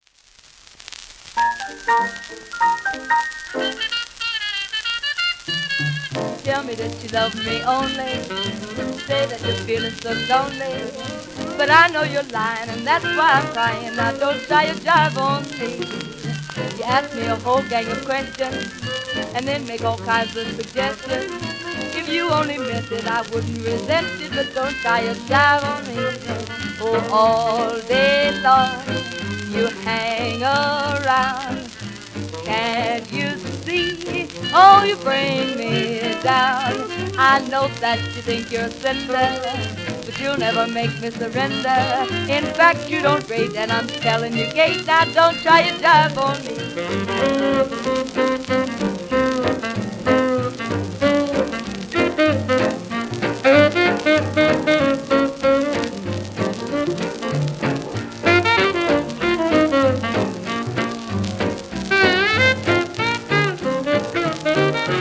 1938年録音